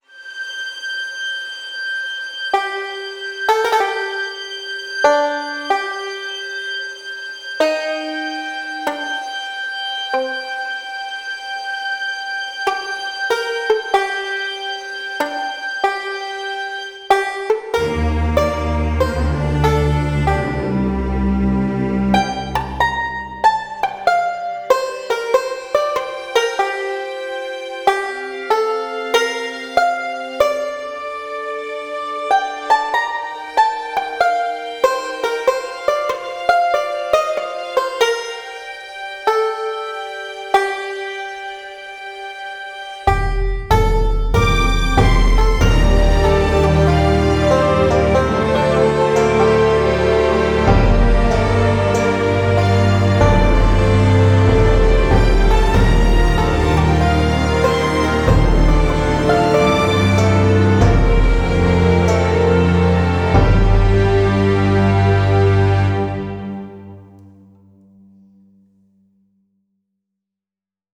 epic-cowboy-orchestral.wav